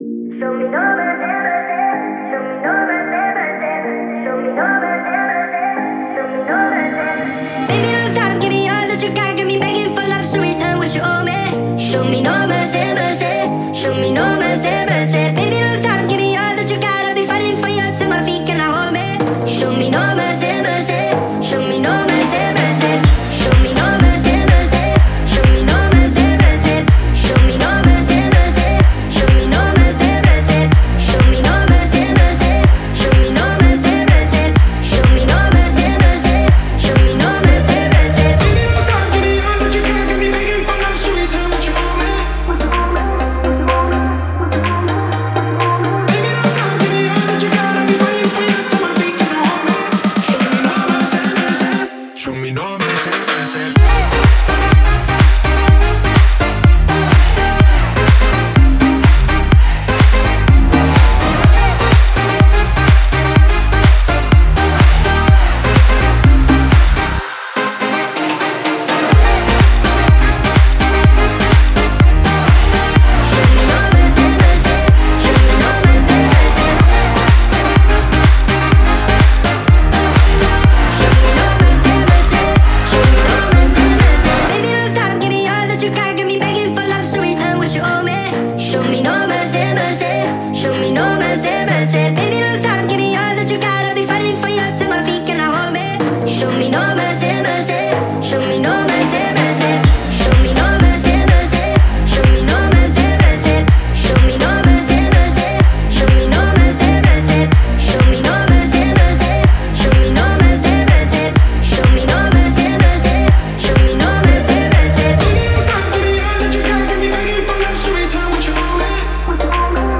это динамичная трек в жанре EDM